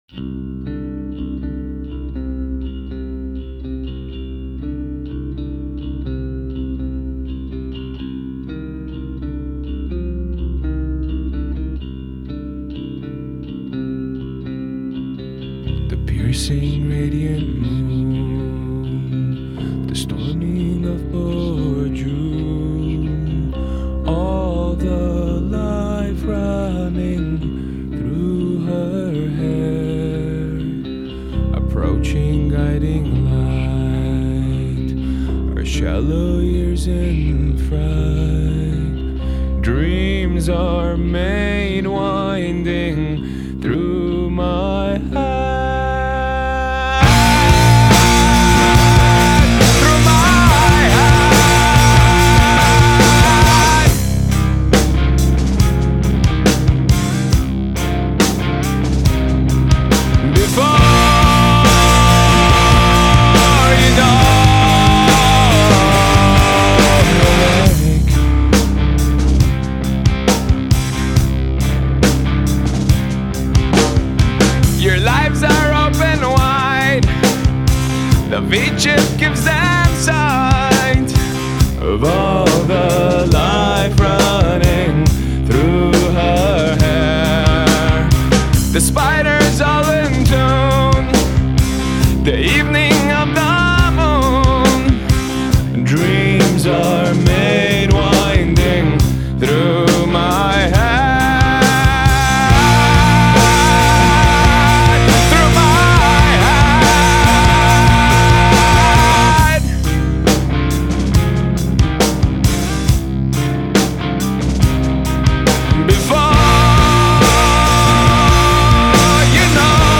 سبک موسیقی آلترناتیو راک
آلترناتیو متال